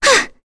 Kirze-Vox_Damage_kr_02.wav